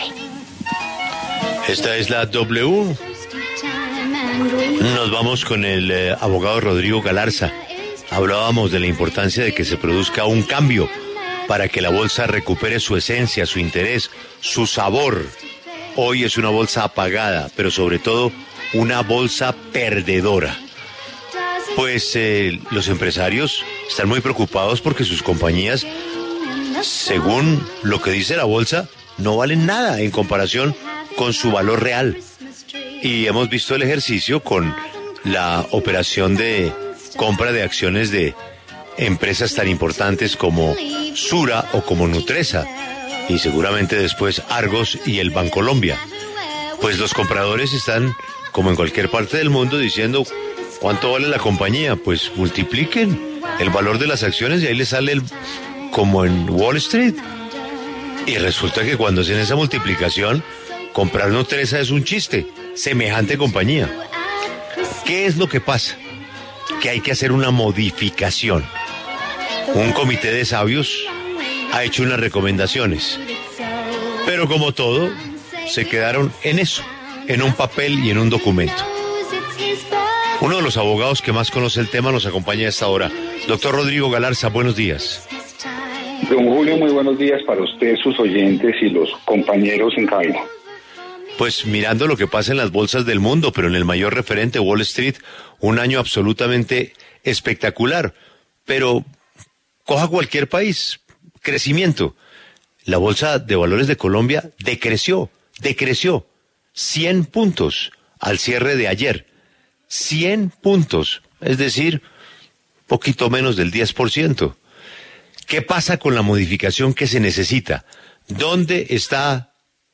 En diálogo con La W